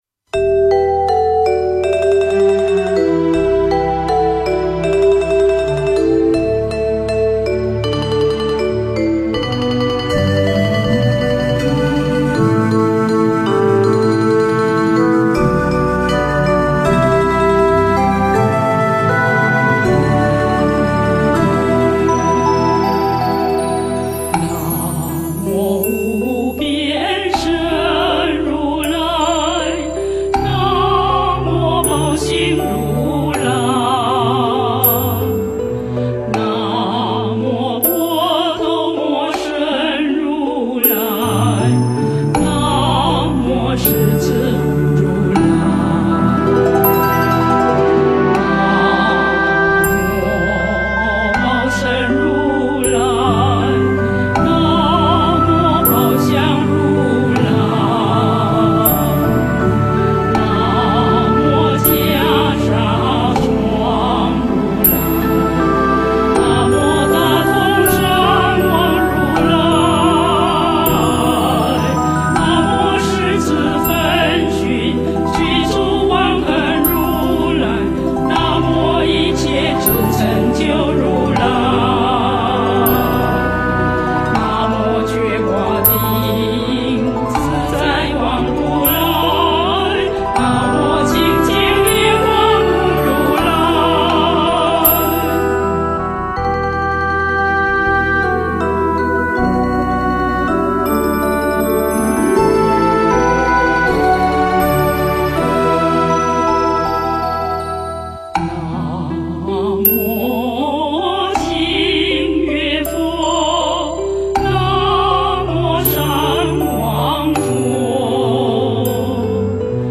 地藏经 - 诵经 - 云佛论坛
地藏经 诵经 地藏经--佛教音乐 点我： 标签: 佛音 诵经 佛教音乐 返回列表 上一篇： 古剎晚鐘(二) The Sound of Bell Emitted in the Eving from an Ancient Temple II 下一篇： 佛母准提神咒 相关文章 菩提树--新韵传音 菩提树--新韵传音...